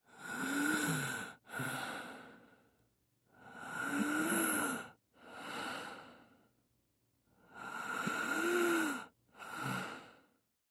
На этой странице собраны звуки хрипов разного характера: сухие и влажные, свистящие и жужжащие.
Мужской хриплый вздох